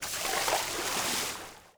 SPLASH_Movement_02_mono.wav